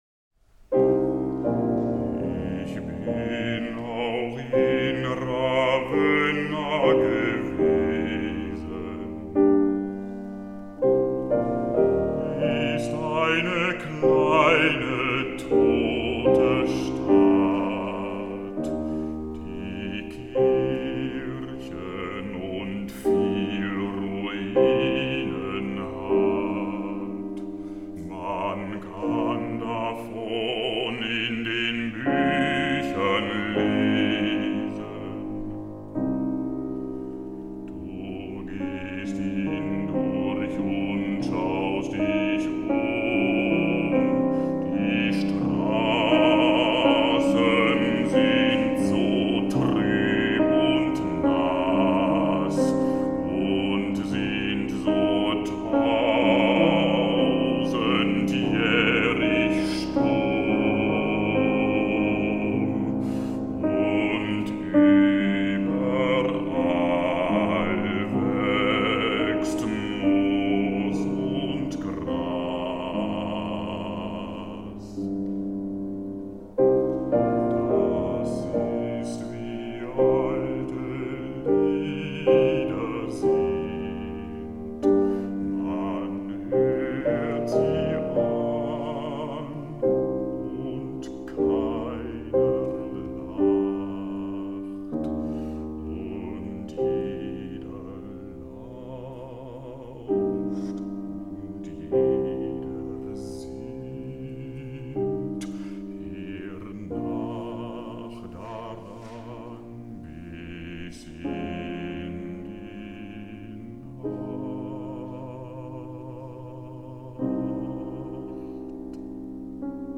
Bass
piano